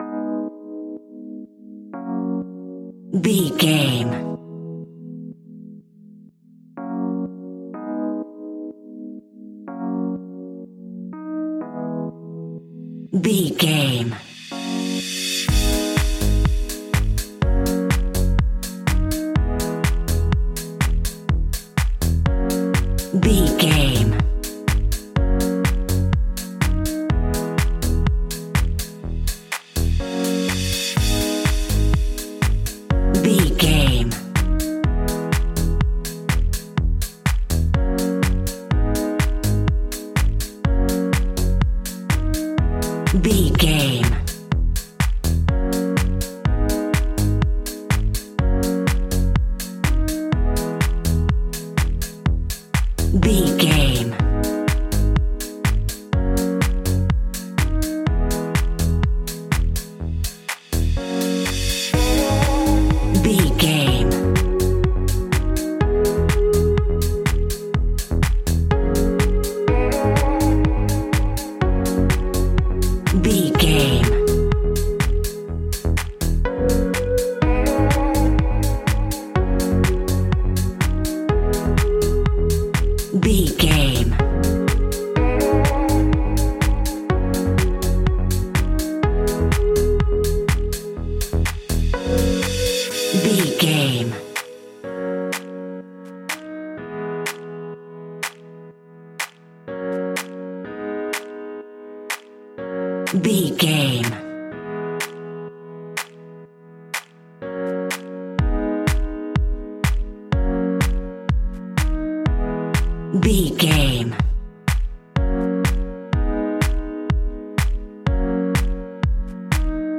Aeolian/Minor
G#
groovy
uplifting
driving
energetic
repetitive
drum machine
synthesiser
piano
electro house
funky house
synth leads
synth bass